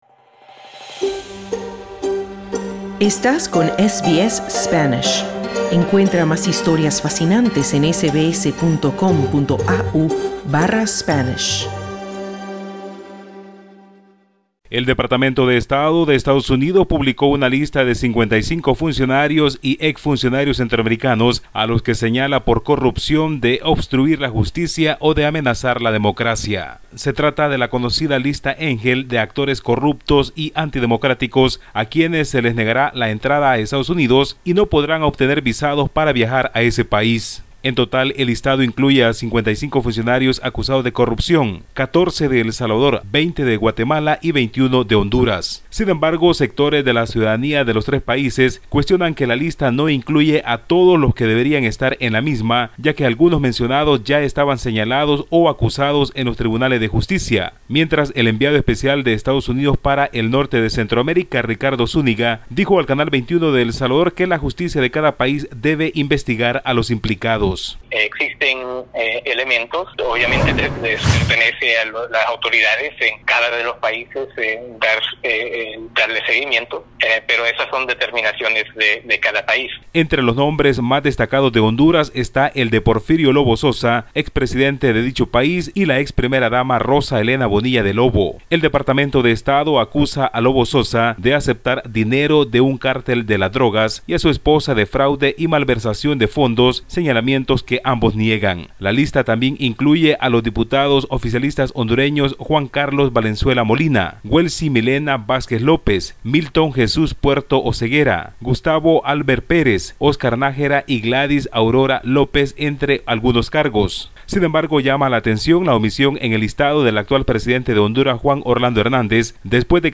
Estados Unidos anunció un listado con medio centenar de funcionarios o exfuncionarios centroamericanos implicados en corrupción y actos antidemocráticos. Los sectores sociales cuestionan ese listado porque no incluye a otros presuntos corruptos, sino, a varios que ya fueron procesados. Escucha el informe de nuestro corresponsal en centroamérica